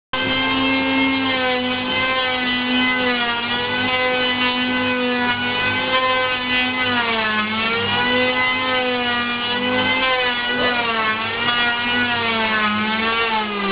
Stora verktygsdagen på Sandskär.
*Brum*  Elhyvlar låter illa!